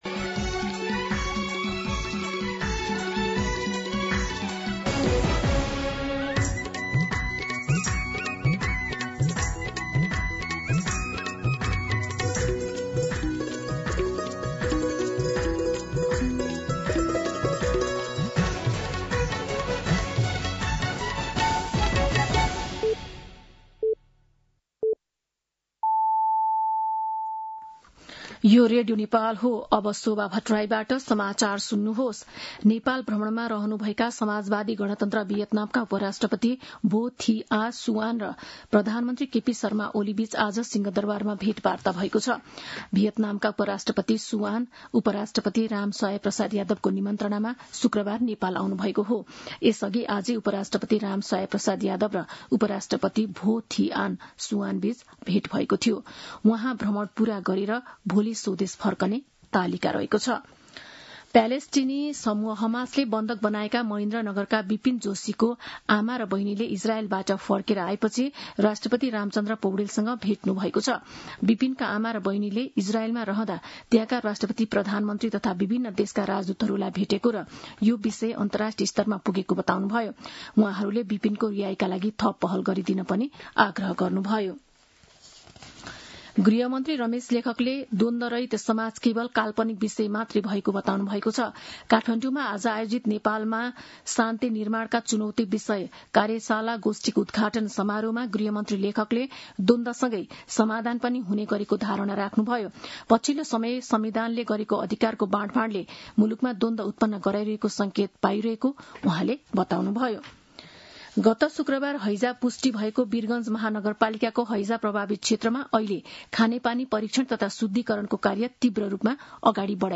दिउँसो ४ बजेको नेपाली समाचार : ८ भदौ , २०८२